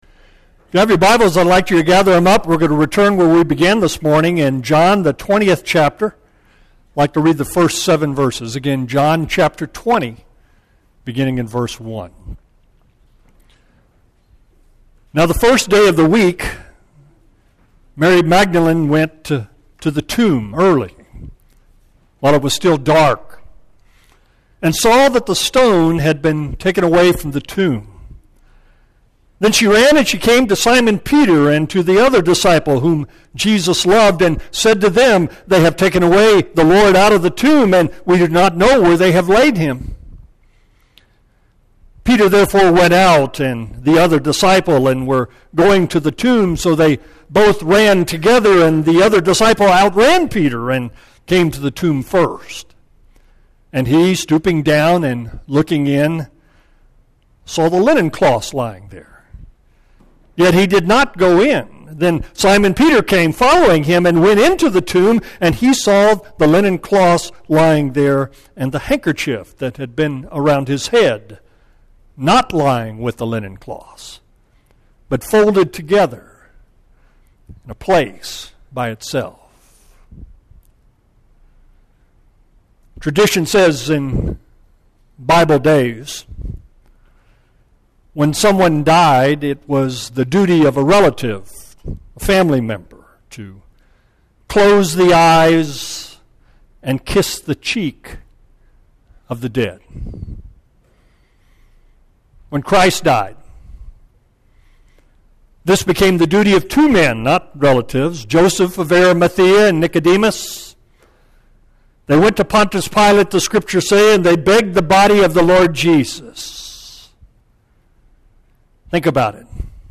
Audio Sermons The Napkin Is Still Folded!